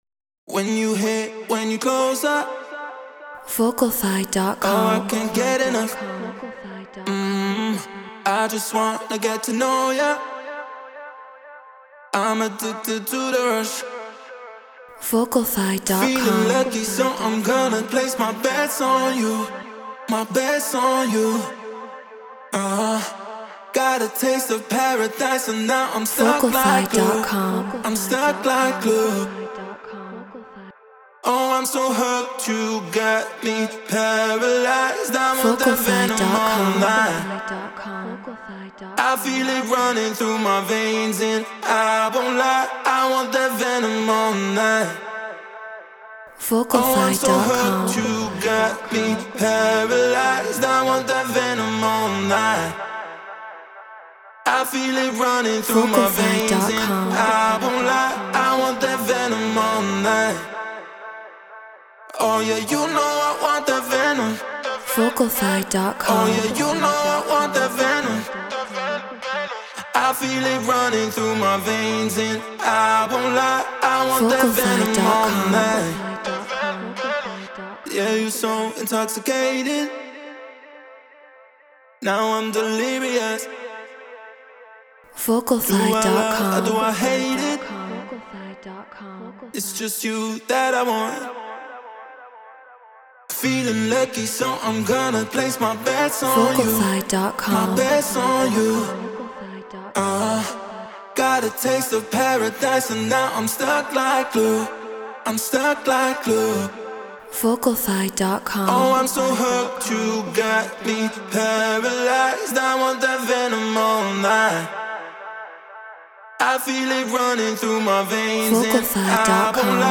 House 124 BPM Amin
Neumann TLM 103 Focusrite Scarlett Pro Tools Treated Room